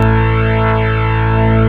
54_17_organ-A.wav